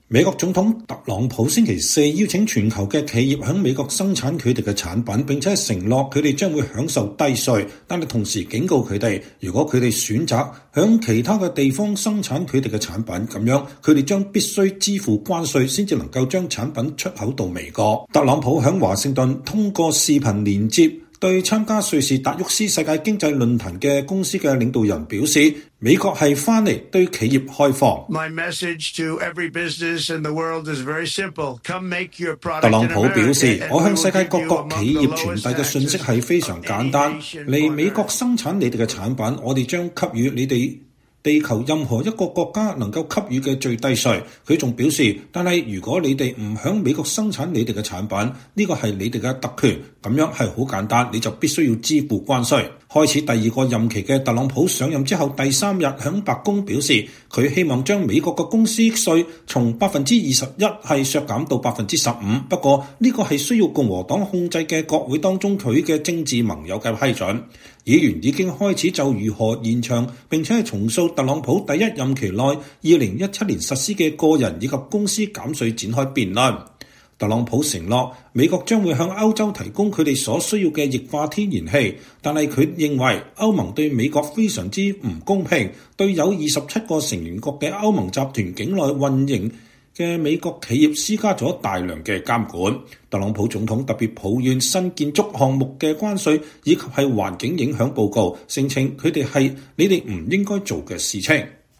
“美國回來了，對企業放開。”特朗普在華盛頓透過視訊連結對參加瑞士達沃斯世界經濟論壇的公司領導人說。